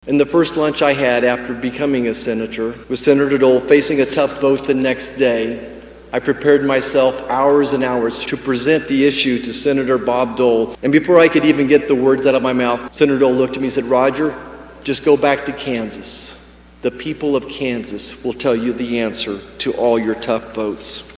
Senator Roger Marshall in his speech says Dole never lost sight of his values that were forged living in Russell.